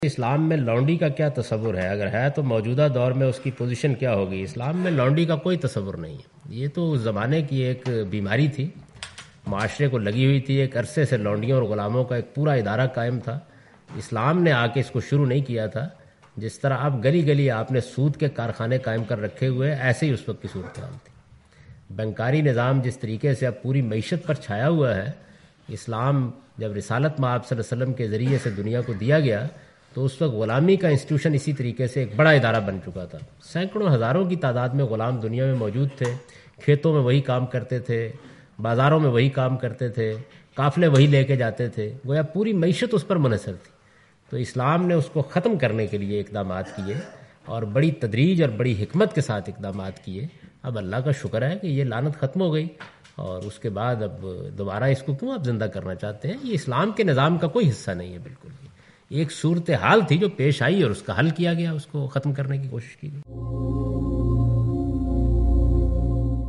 Question and Answers with Javed Ahmad Ghamidi in urdu